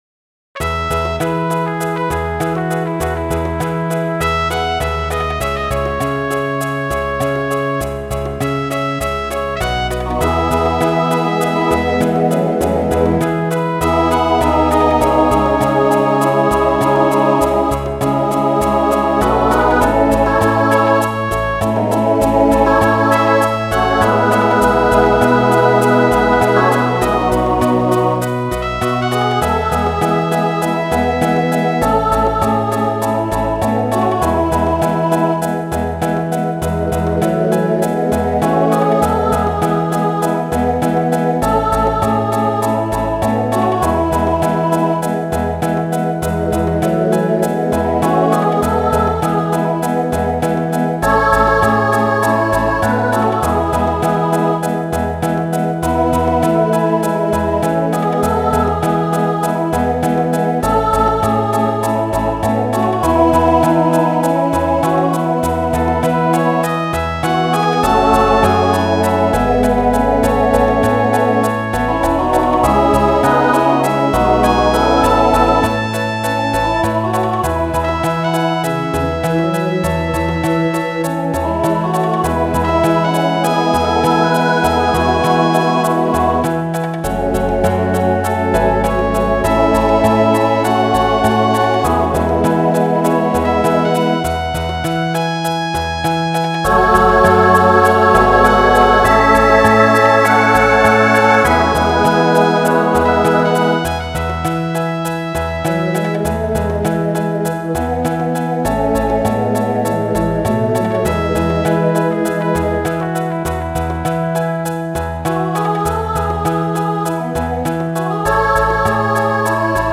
Voicing SATB Instrumental piano Genre Rock
2010s Show Function Ballad